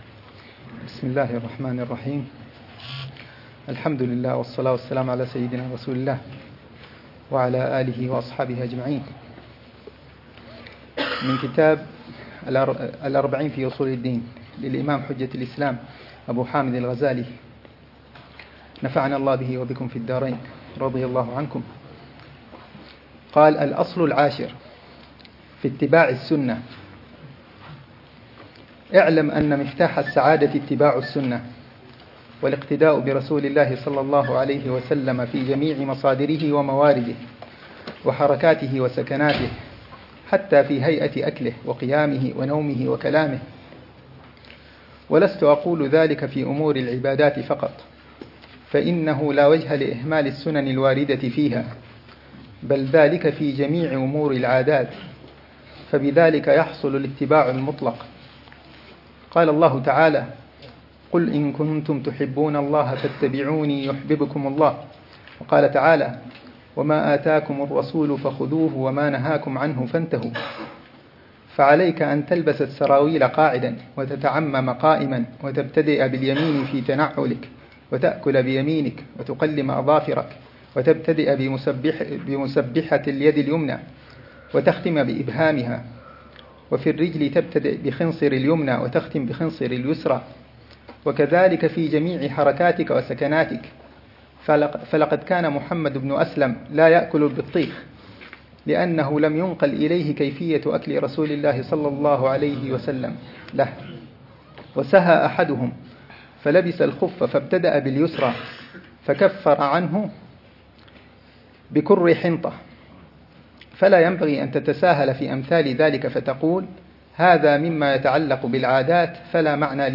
الدرس الثامن عشر للعلامة الحبيب عمر بن محمد بن حفيظ في شرح كتاب: الأربعين في أصول الدين، للإمام الغزالي .